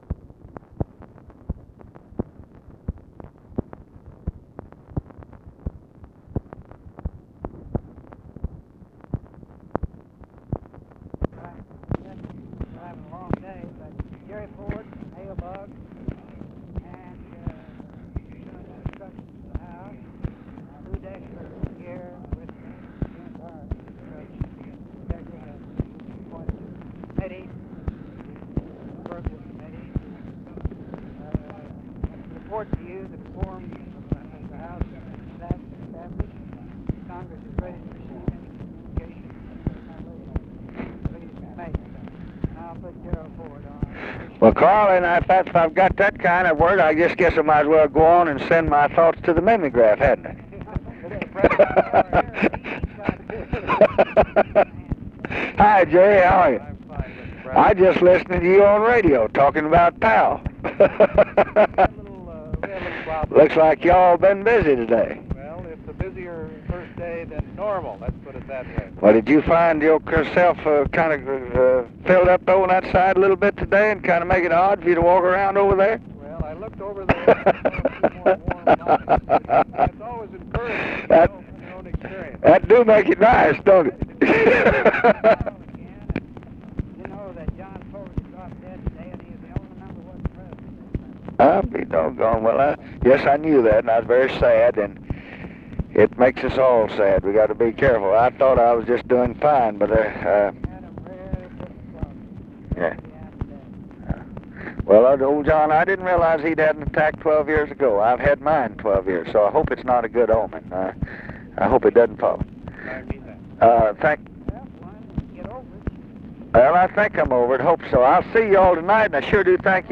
Conversation with CARL ALBERT, GERALD FORD and HALE BOGGS, January 10, 1967
Secret White House Tapes